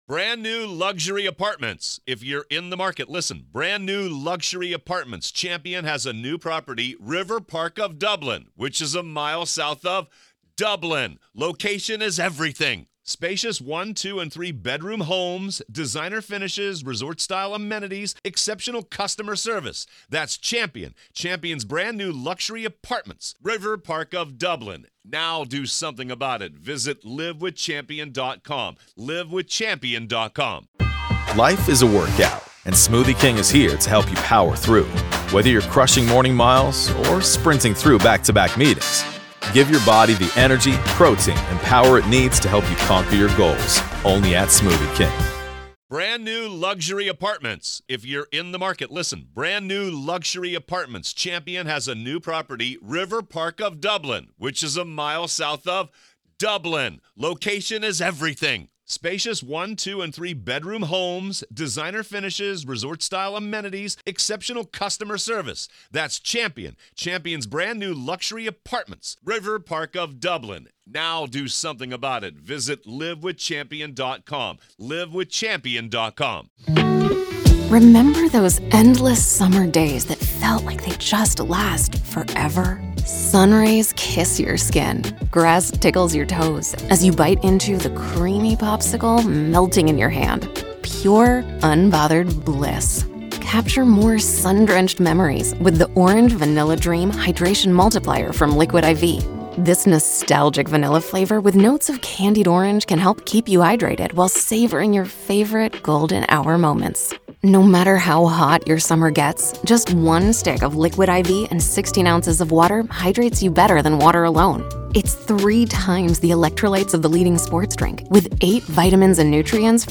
Court Audio-NEVADA v. Robert Telles DAY 5 Part 1